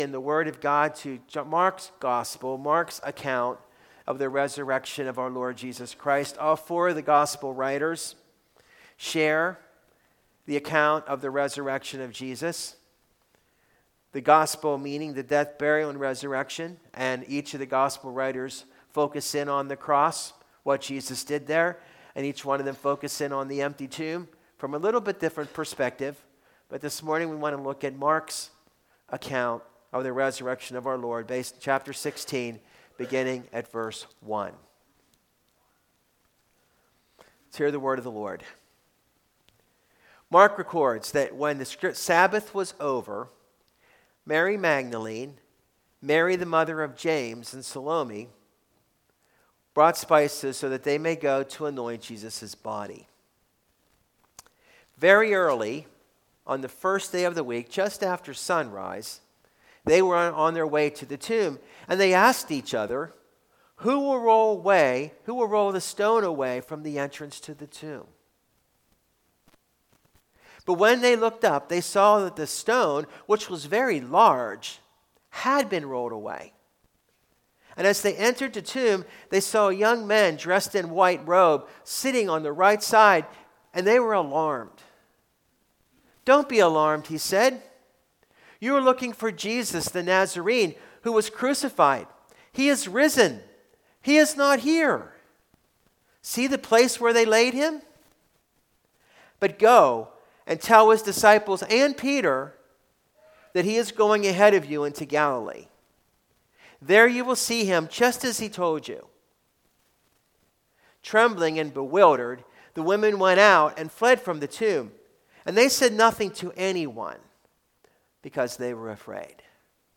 Easter Sunday